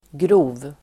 Uttal: [gro:v]